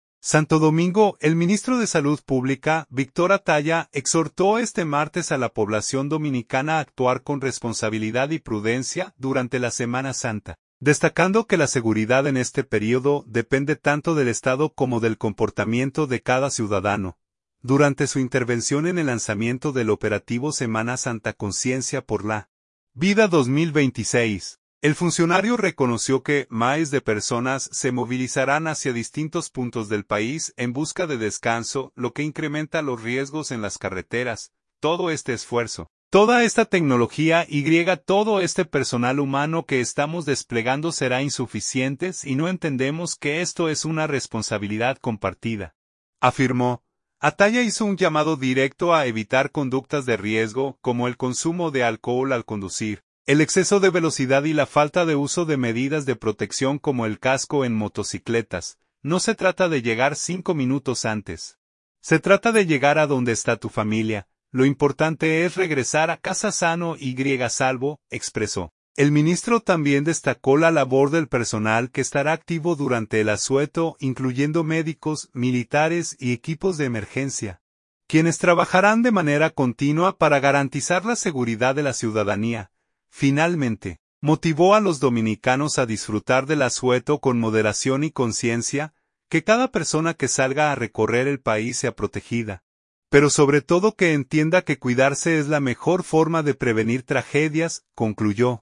Durante su intervención en el lanzamiento del operativo “Semana Santa Conciencia por la Vida 2026”, el funcionario reconoció que miles de personas se movilizarán hacia distintos puntos del país en busca de descanso, lo que incrementa los riesgos en las carreteras.